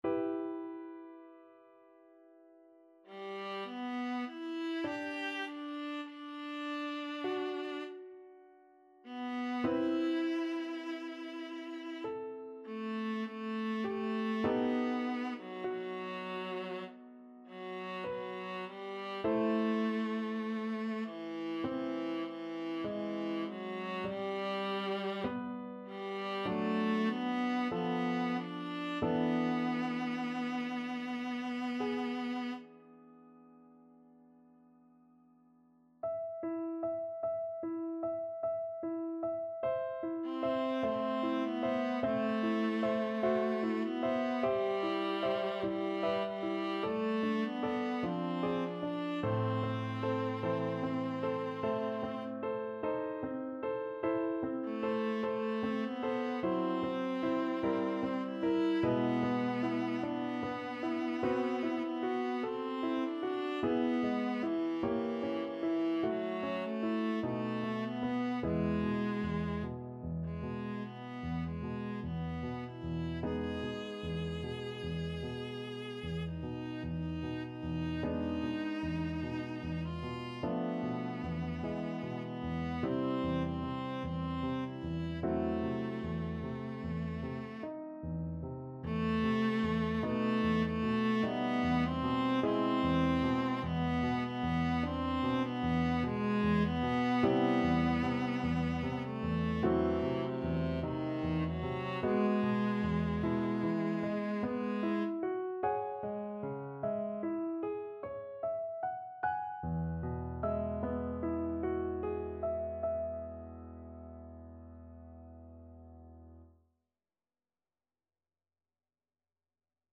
Viola
G major (Sounding Pitch) (View more G major Music for Viola )
~ = 100 Lento =50
Classical (View more Classical Viola Music)